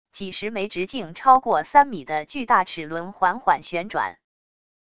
几十枚直径超过三米的巨大齿轮缓缓旋转wav音频